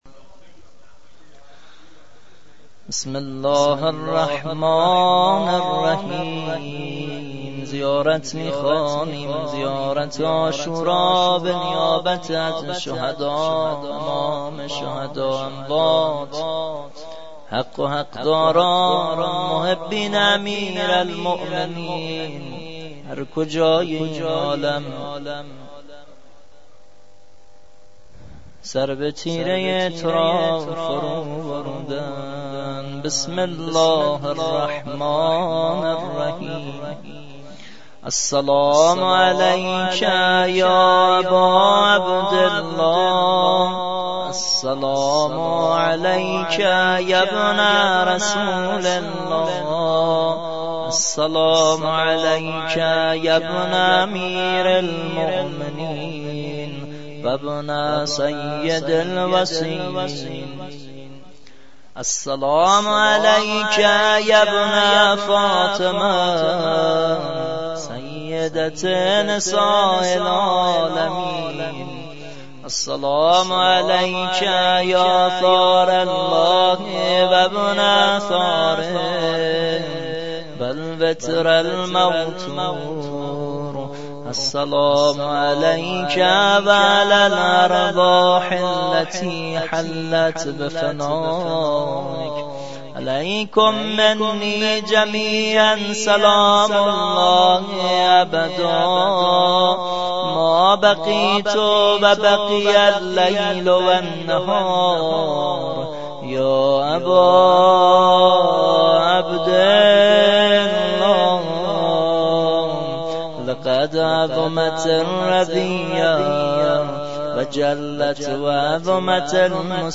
مراسم هفتگی